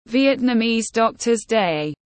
Ngày thầy thuốc Việt Nam tiếng anh gọi là Vietnamese Doctor’s Day, phiên âm tiếng anh đọc là /ˌvjɛtnəˈmiːz ˈdɒktəz deɪ/
Vietnamese Doctor’s Day /ˌvjɛtnəˈmiːz ˈdɒktəz deɪ/
Vietnamese-Doctors-Day-.mp3